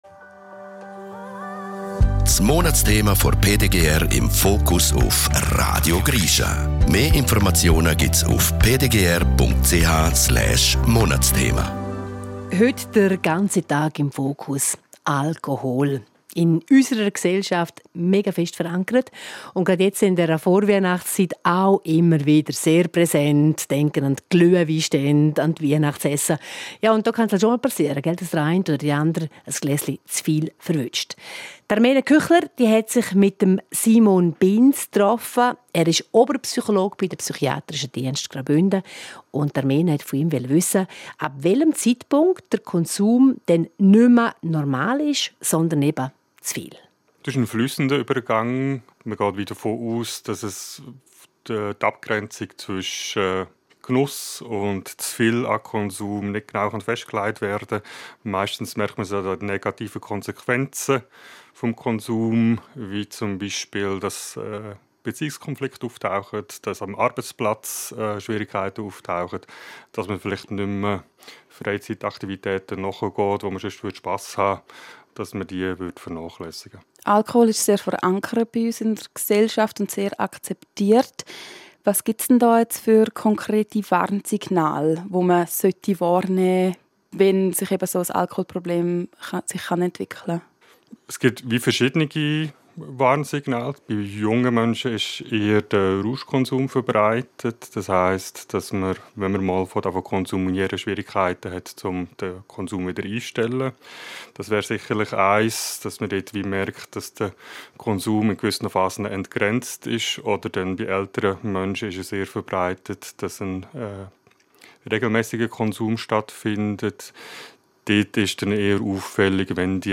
Radiobeiträge